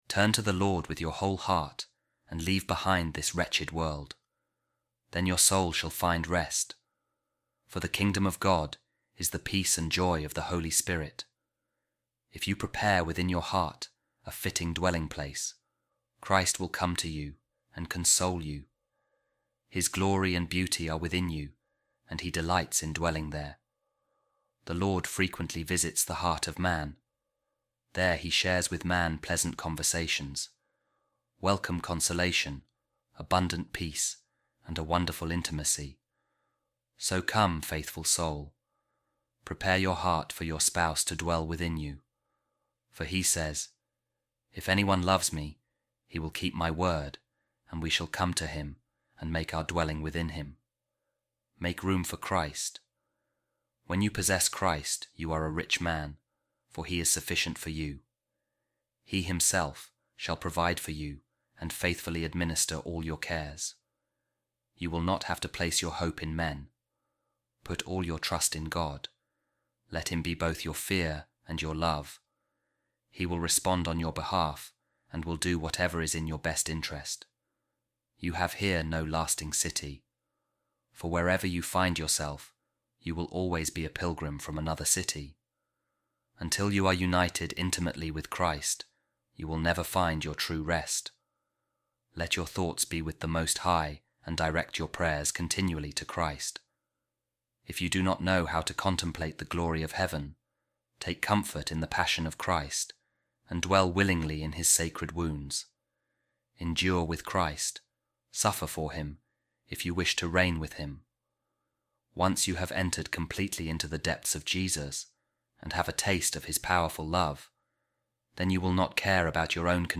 A Reading From The Imitation Of Christ | The Kingdom Of God Is Peace And Joy In The Holy Spirit